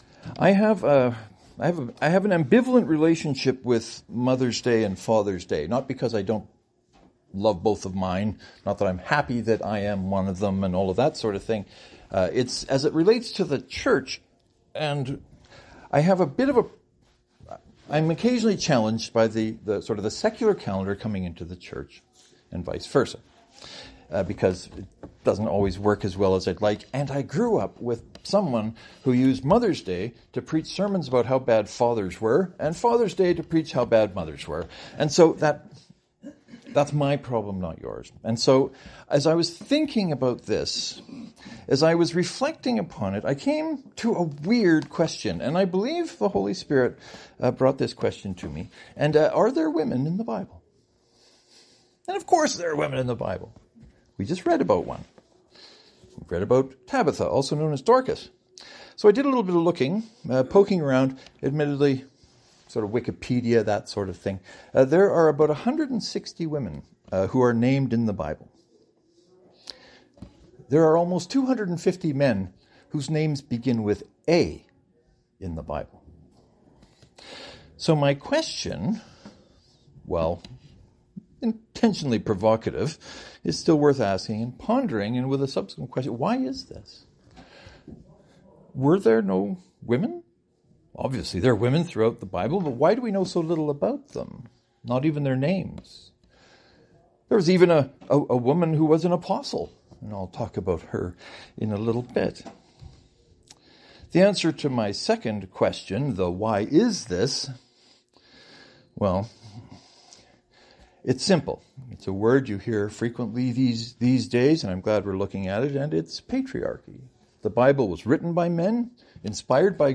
This Sunday was our first Sunday in our new space. I think you will notice some difference in the sound quality (much lower ceiling and closer walls) which I think is better.